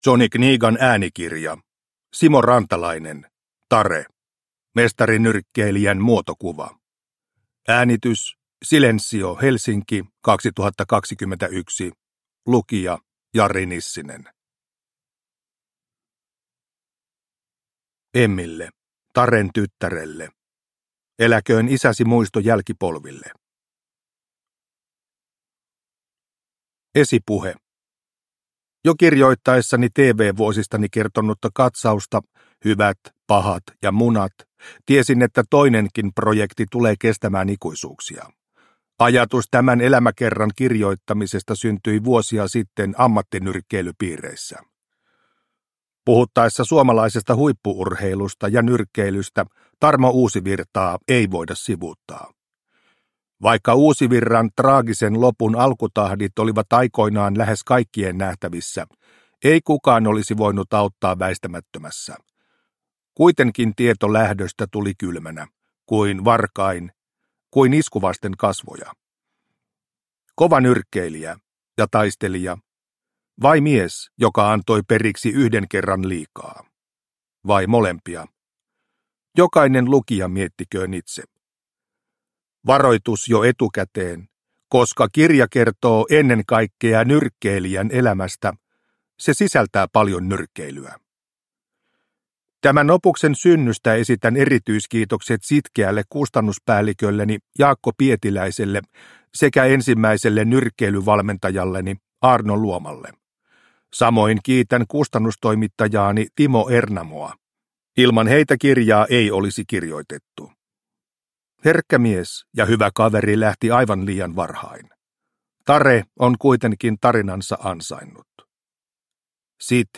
Tare – Ljudbok – Laddas ner